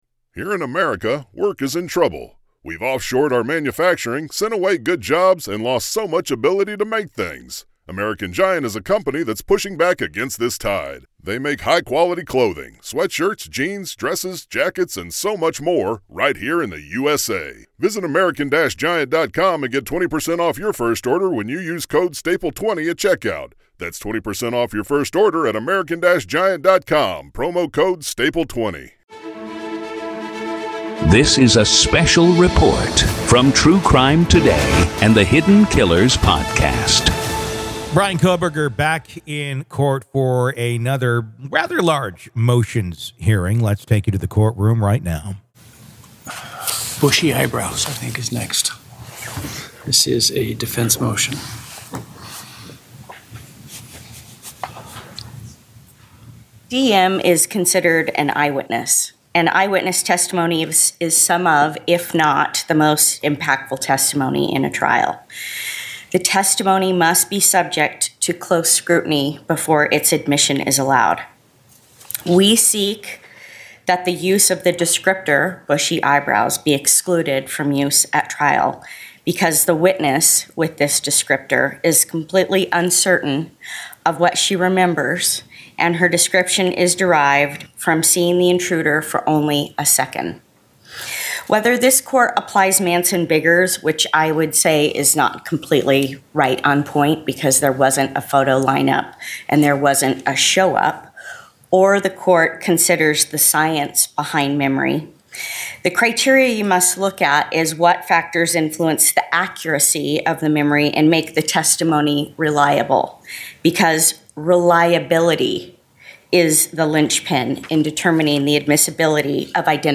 RAW COURT AUDIO | Idaho Student Murders — ID v. Bryan Kohberger PART 3